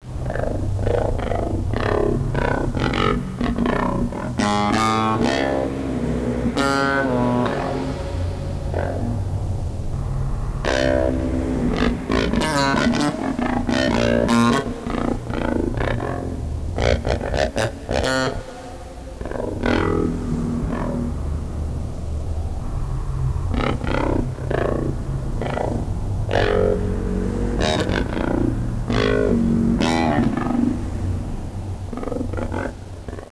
some downloads from the realtime sound generation in the installation motion tracker.die samples der hier zur verfügung stehenden sounds sind ausschließlich durch das verhalten des sensibilisierten bildes und des raumes erzeugt worden. auf eine nachträgliche manipulation der sounds wurde verzichtet, da innerhalb des projektes nur eine akzeptanz interaktiv erzeugter klänge vorhanden ist.
die ersten testaufnahmen für motion tracker fanden im hamburger gvoon studio im dezember 1996 statt. the tracks and some downloads in low quality 8 bit 22 khz, type aiff, 30 sec.: track one: total running 40:12 min.